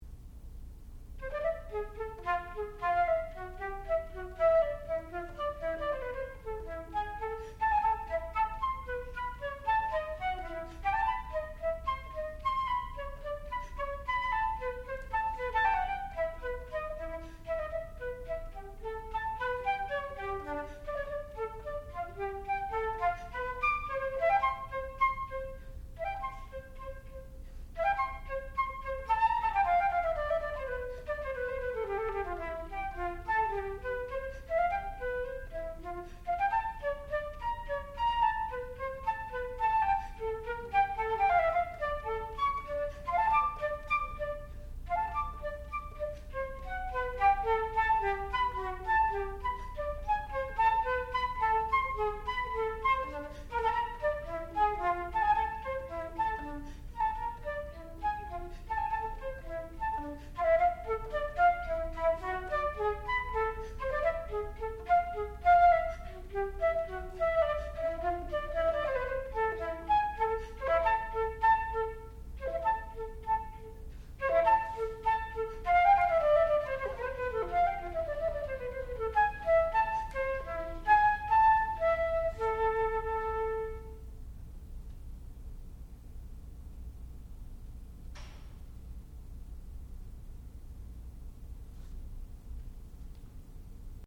sound recording-musical
classical music
Advanced Recital